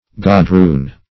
Search Result for " godroon" : The Collaborative International Dictionary of English v.0.48: Godroon \Go*droon"\ (g[-o]*dr[=oo]n"), n. [F. godron a round plait, godroon.]